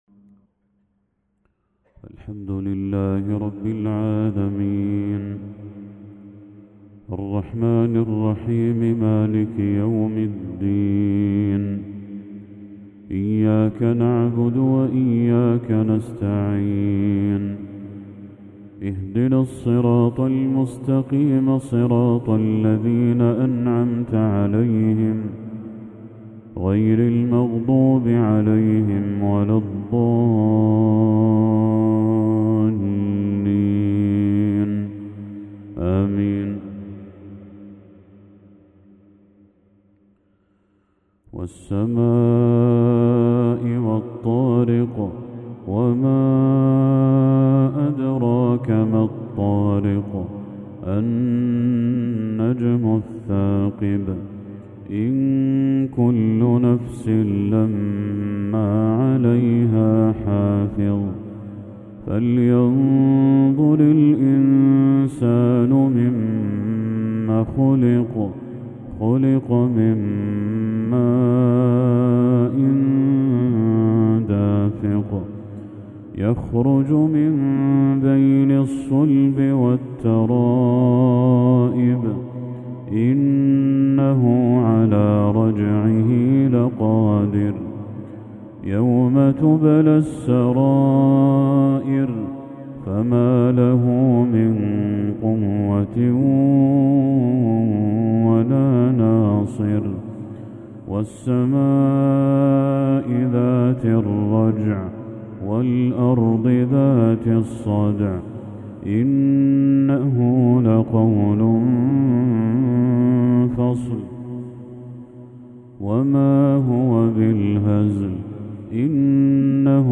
تلاوة لسورتي الطارق و الهمزة
مغرب 24 ذو الحجة 1445هـ